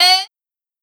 uk drill.wav